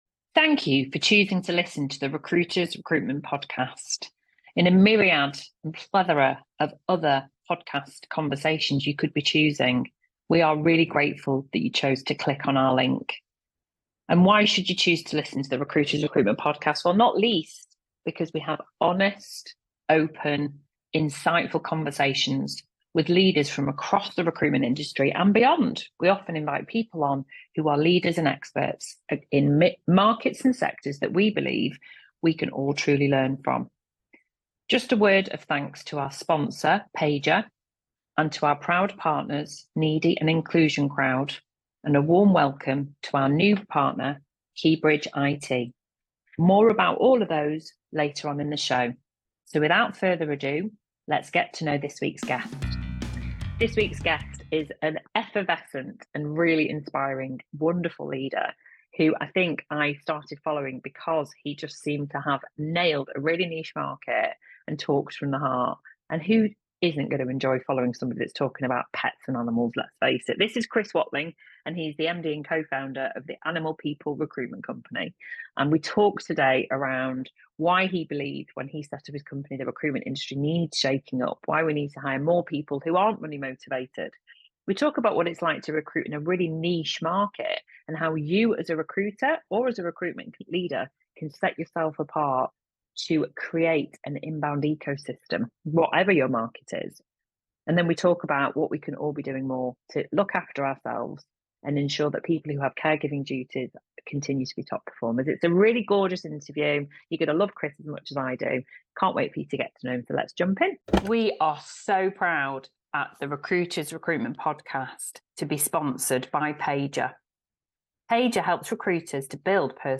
Join us for an insightful conversation filled with practical advice and industry expertise.